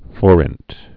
(fôrĭnt)